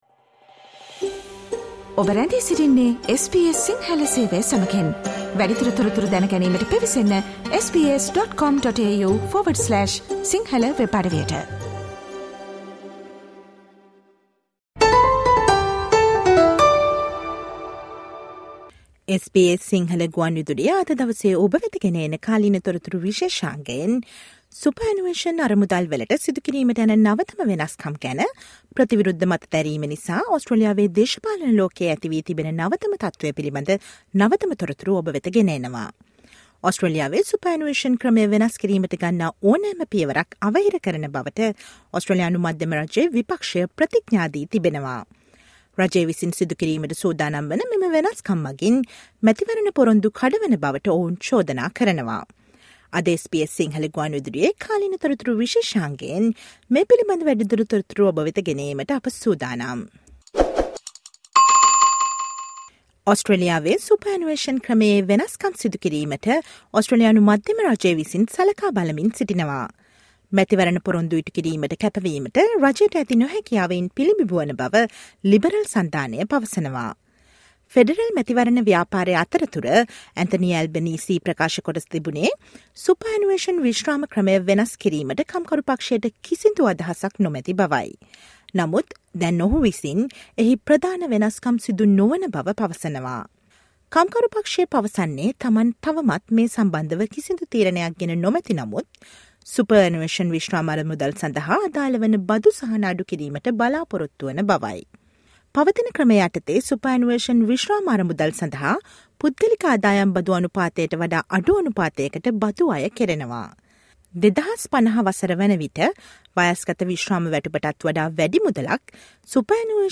Listen to the SBS Sinhala radio current affair feature on the newest updates in political world of Australia on the potential superannuation changes.